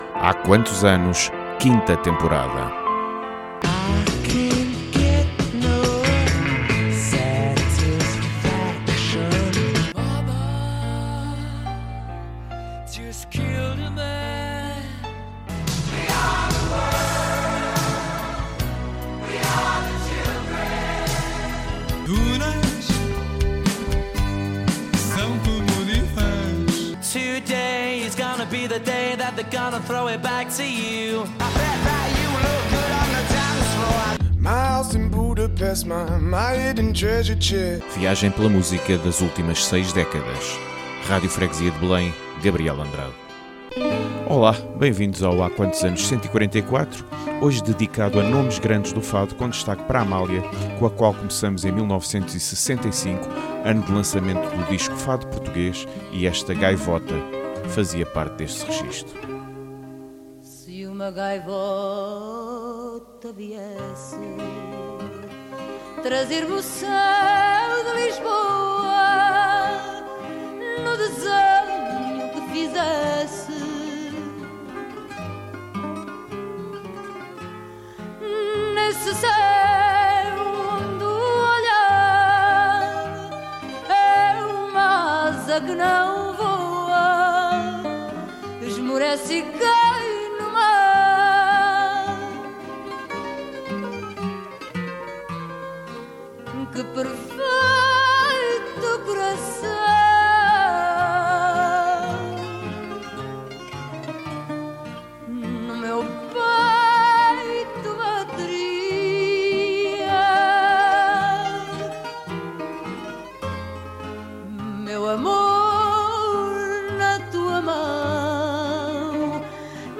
Música das últimas seis décadas.